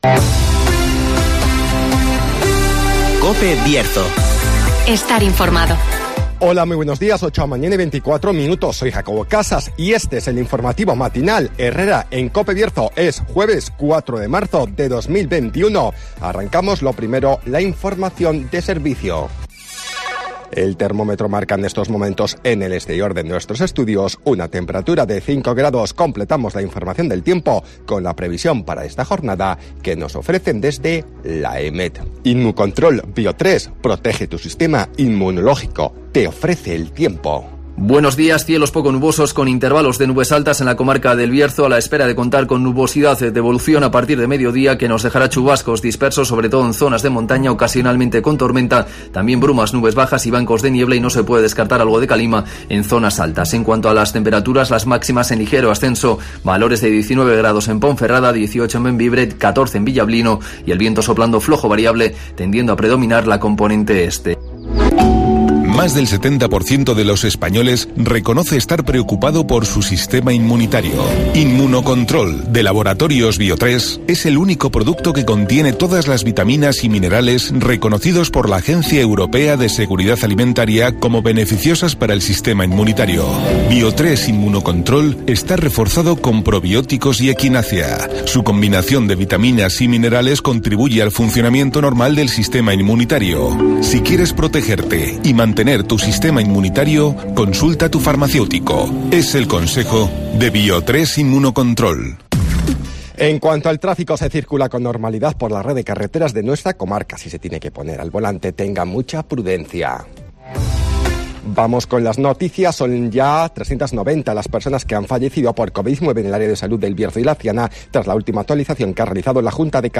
AUDIO: Repaso a la actualidad informativa del Bierzo. Escucha aquí las noticias de la comarca con las voces de los protagonistas.